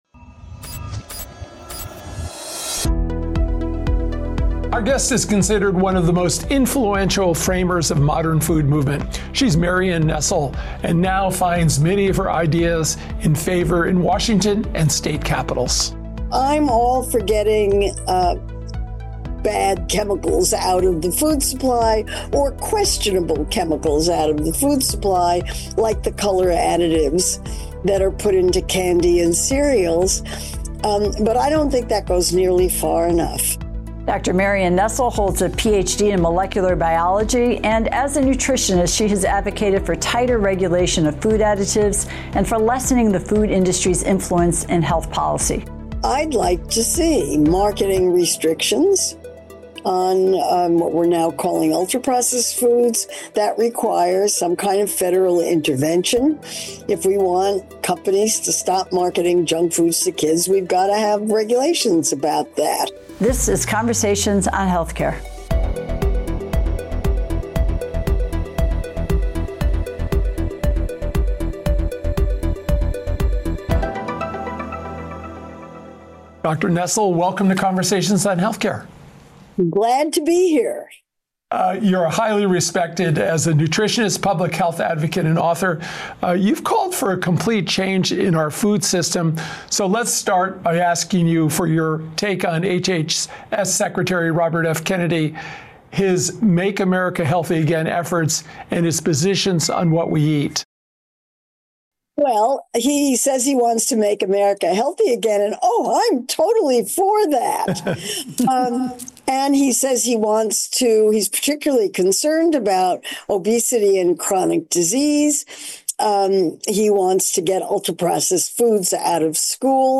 Click now to view the entire interview, including Dr. Nestle’s reactions to the food industry’s pushback to her concerns.